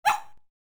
Bark1.wav